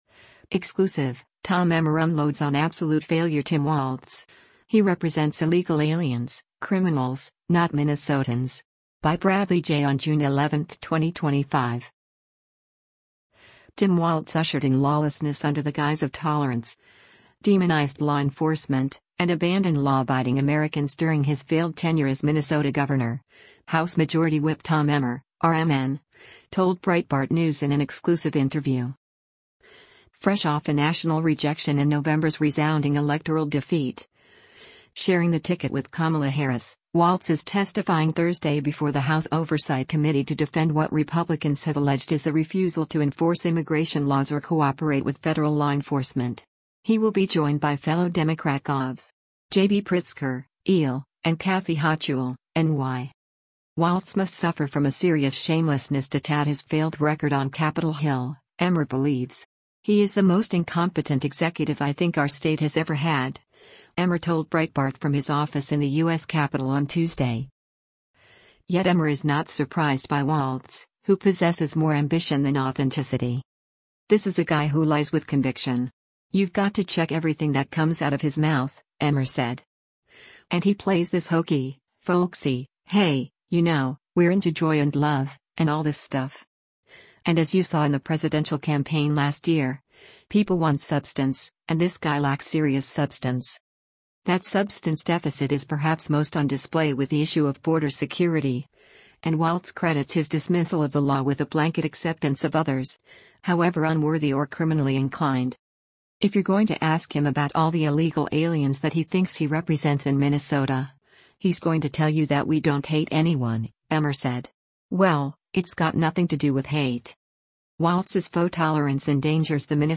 Tim Walz ushered in lawlessness under the guise of tolerance, demonized law enforcement, and abandoned law-abiding Americans during his failed tenure as Minnesota governor, House Majority Whip Tom Emmer (R-MN) told Breitbart News in an exclusive interview.
“He is the most incompetent executive I think our state has ever had,” Emmer told Breitbart from his office in the U.S. Capitol on Tuesday.